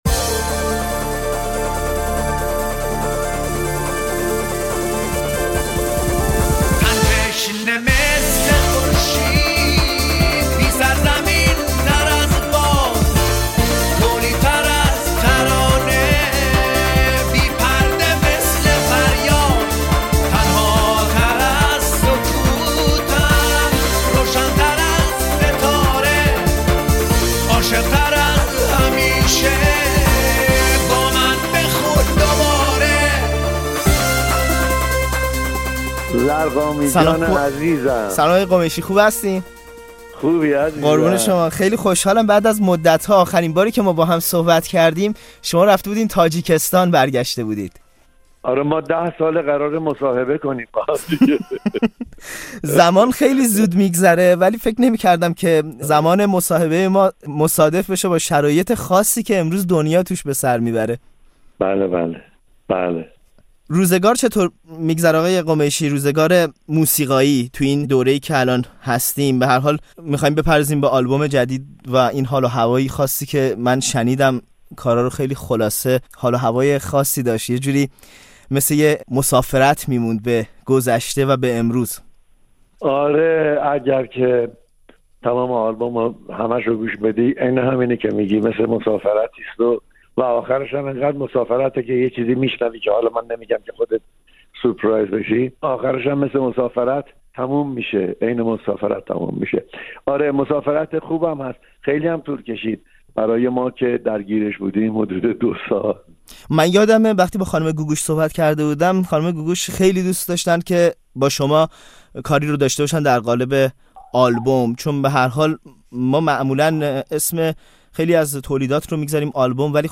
خانه شماره ۱۳: گپ‌وگفت خودمانی با سیاوش قمیشی